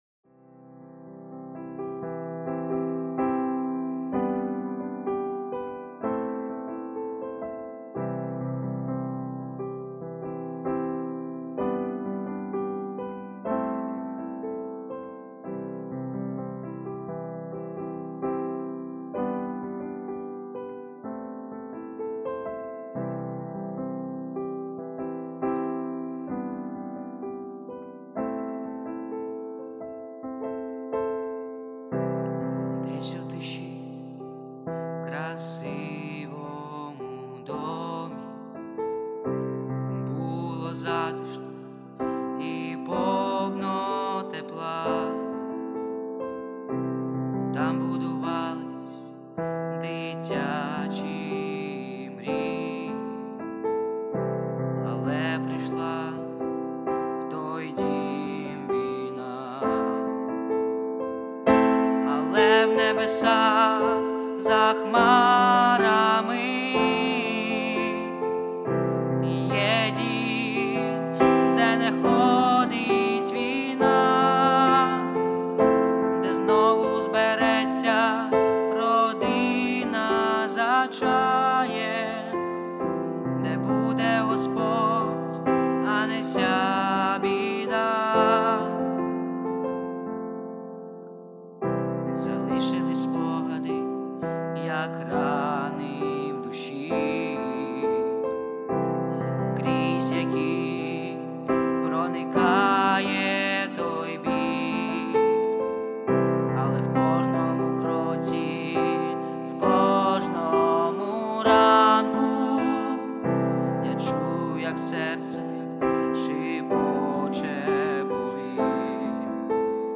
183 просмотра 250 прослушиваний 1 скачиваний BPM: 80